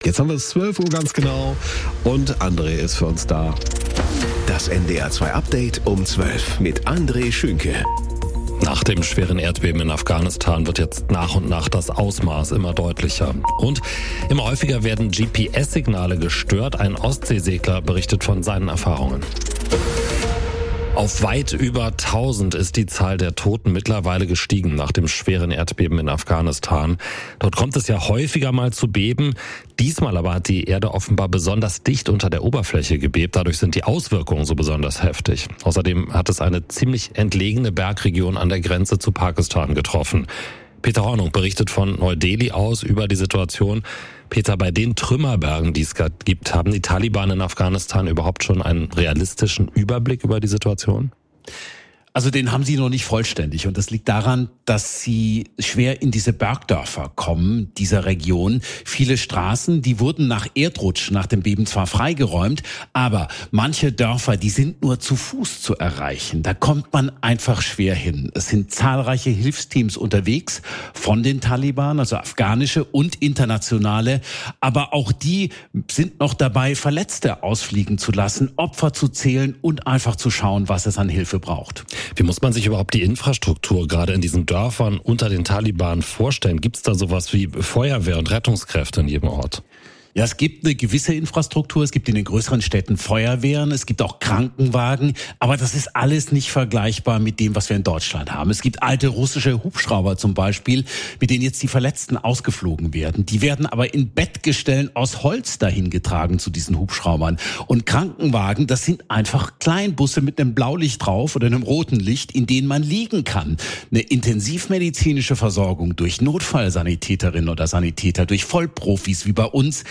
Nach dem schweren Erdbeben in Afghanistan wird nach und nach das Ausmaß immer deutlicher+++Immer häufiger werden GPS-Signale gestört - ein Ostsee-Segler berichtet von seinen Erfahrungen